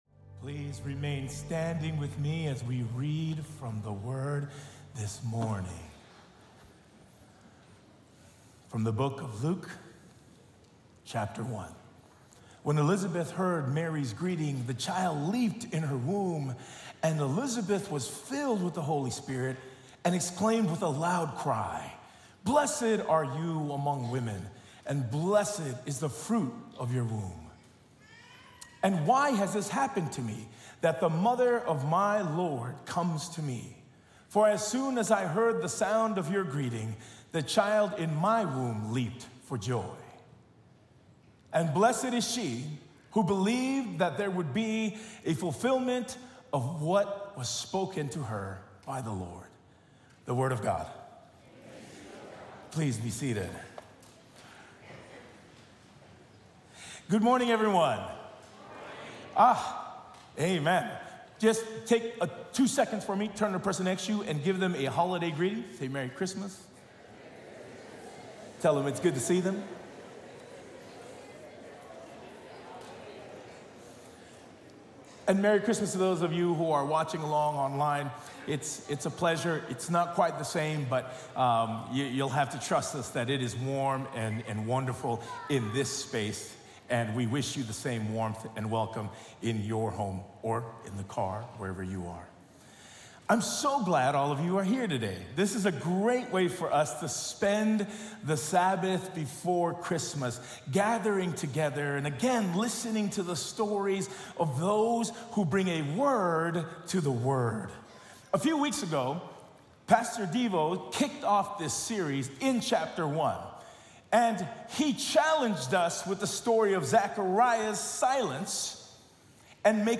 This is the sermon audio podcast for La Sierra University Church.